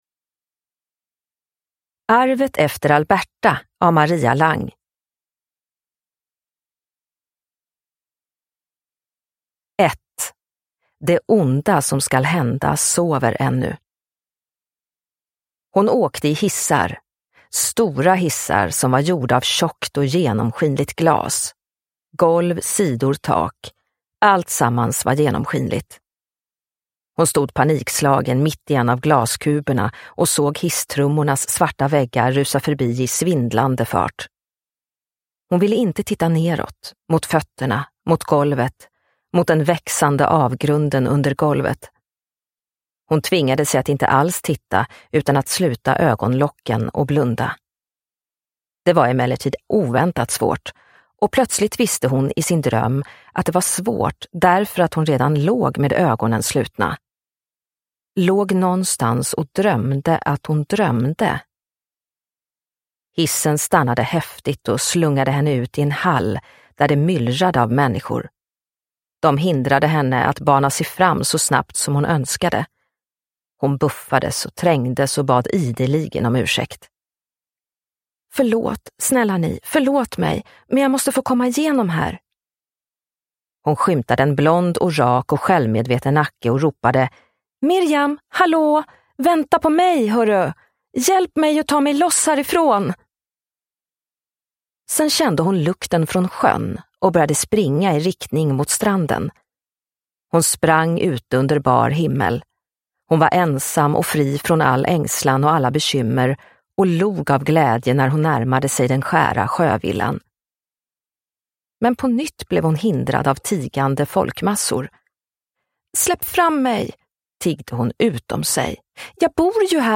Arvet efter Alberta – Ljudbok – Laddas ner
Uppläsare: